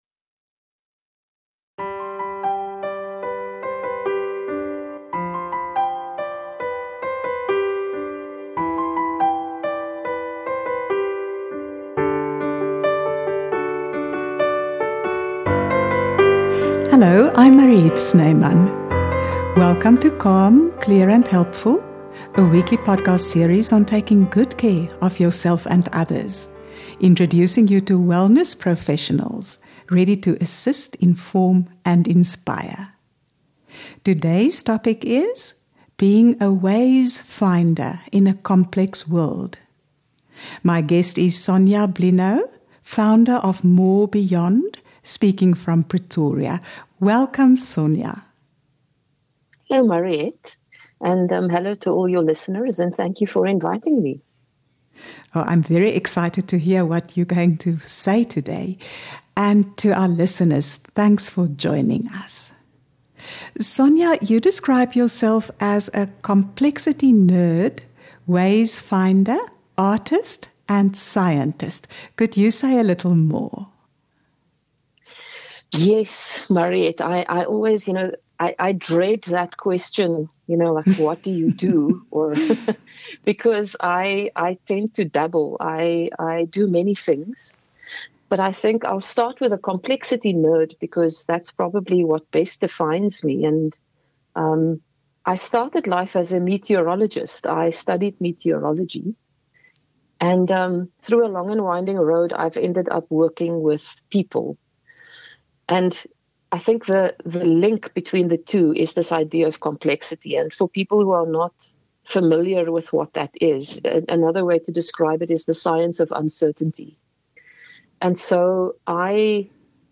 interviews a range of experts on holistic health, love relationships, parenting, and life's phases and challenges.